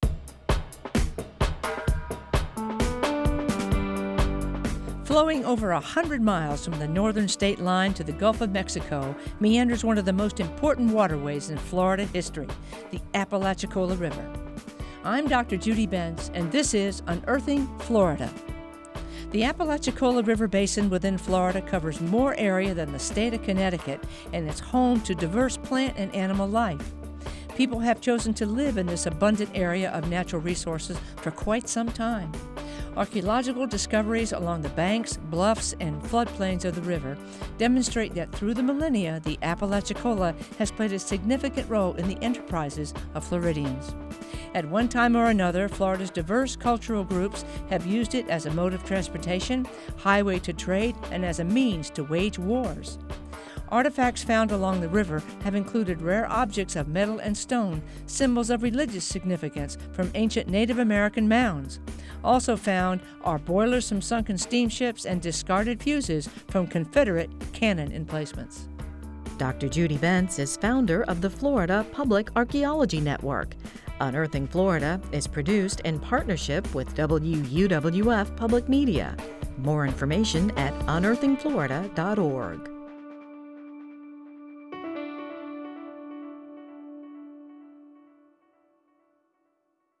Written, narrated, and produced by the University of West Florida, the Florida Public Archaeology Network and WUWF Public Media.